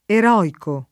eroico [ er 0 iko ]